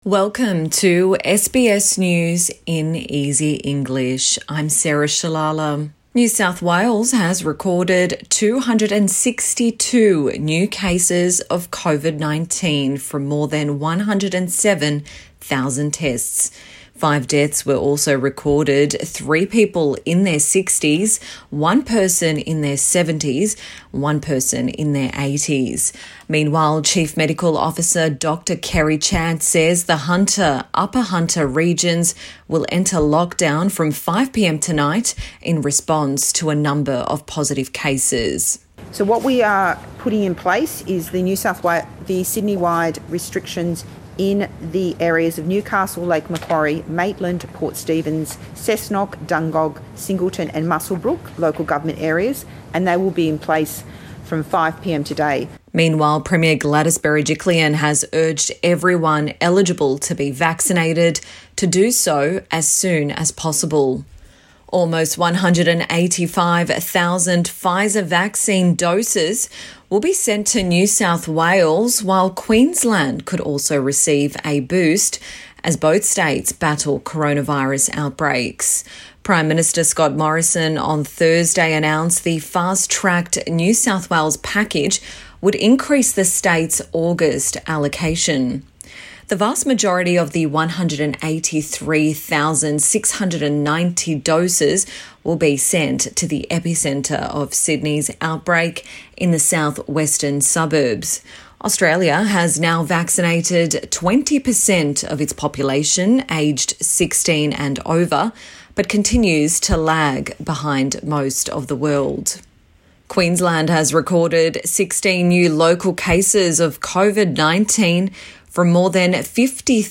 SBS News in Easy English Bulletin 5 August 2021